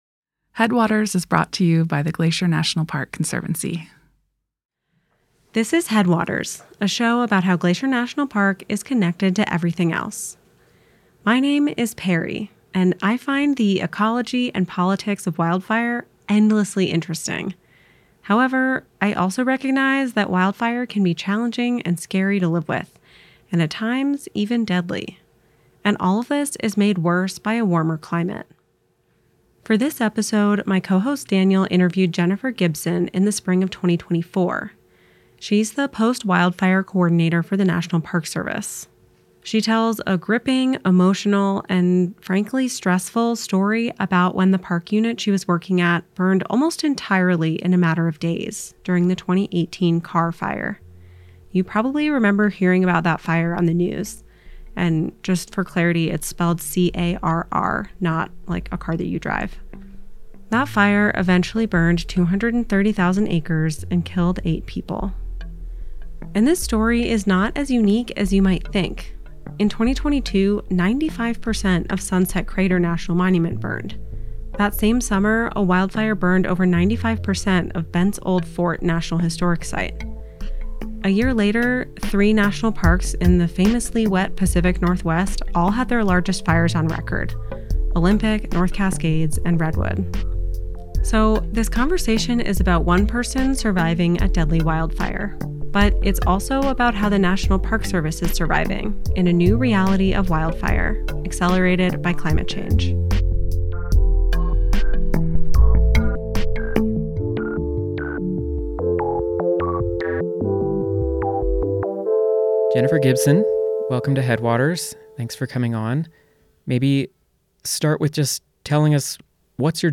[mellow beat begins to play] And this story is not as unique as you might think.
[beat plays for a few seconds and resolves, marking a transition into the interview]